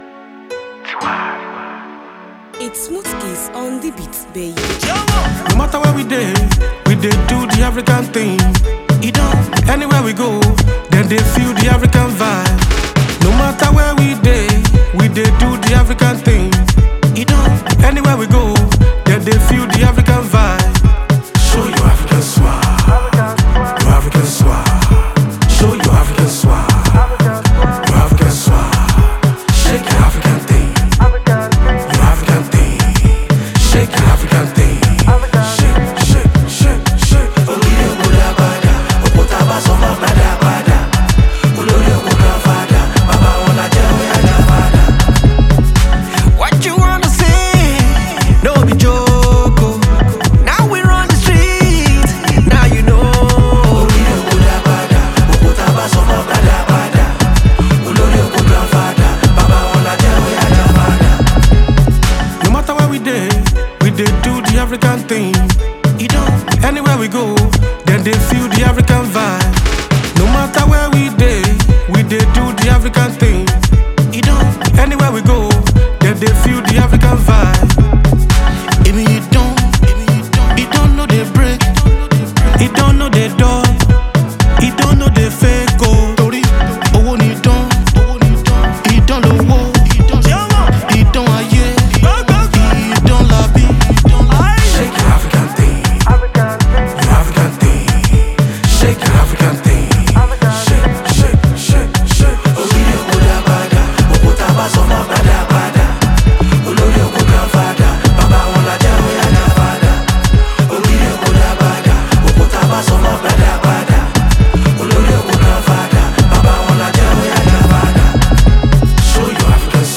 It’s a feel-good tune and a must-have on your playlists.